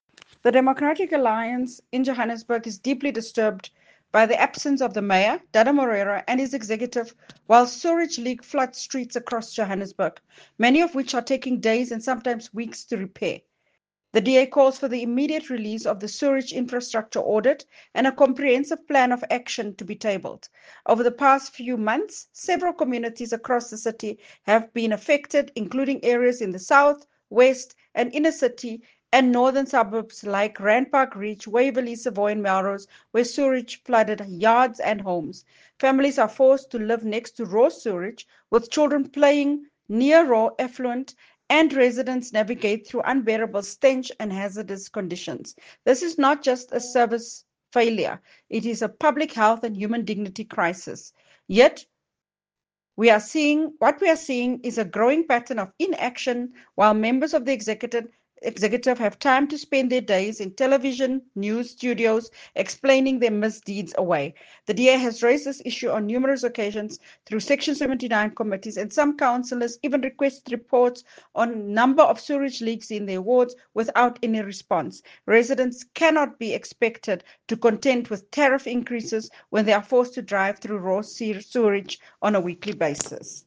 Note to Editors: Please find an English soundbite by Belinda Kayser-Echeozonjoku